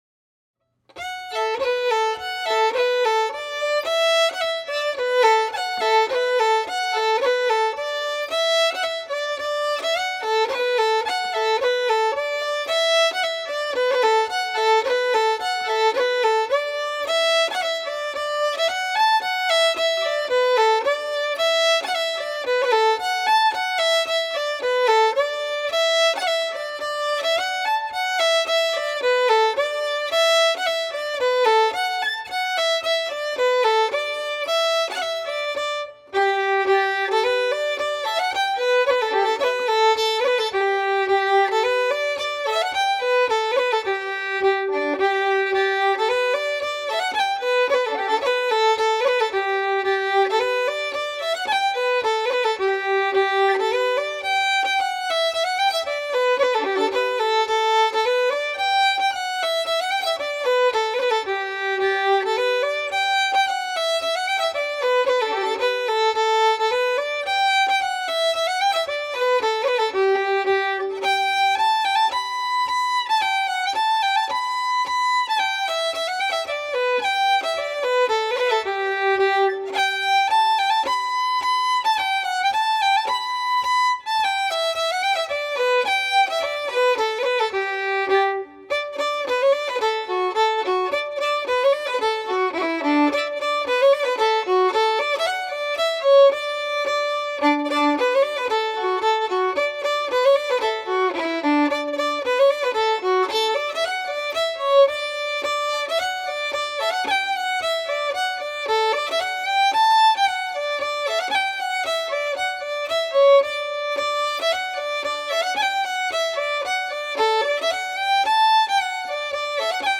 5_polkas.mp3